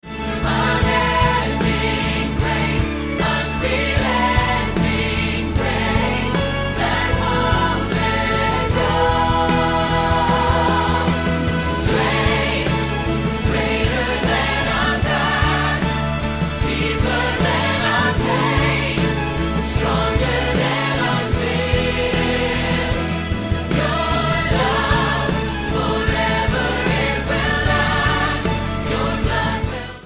Anthem